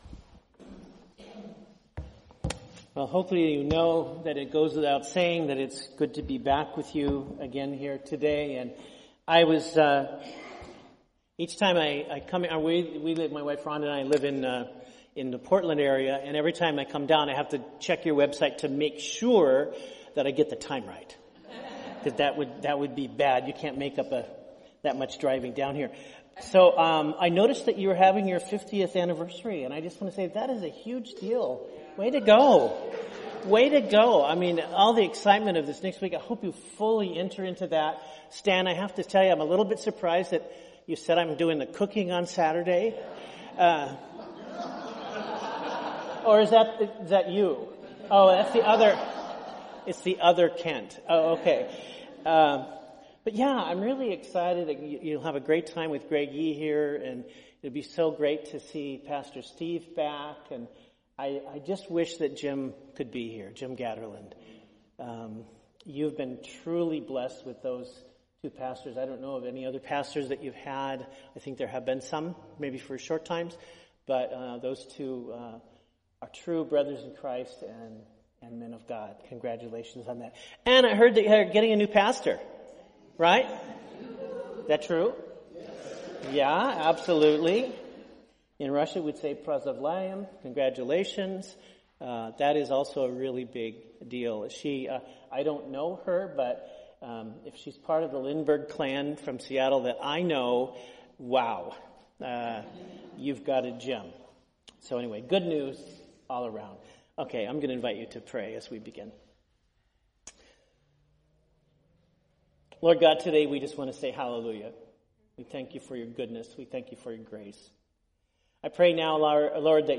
We apologize for the technical mic issues towards the end of the sermon.